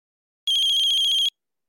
mobile.mp3